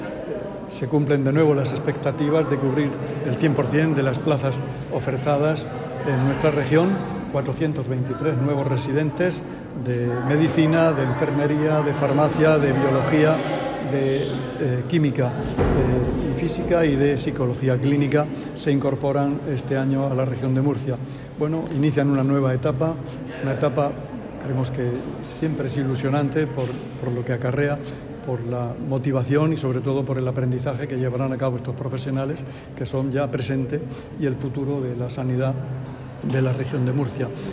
Sonido/ Declaraciones del consejero de Salud en el acto de bienvenida a los 423 residentes que se incorporan a la Región de Murcia.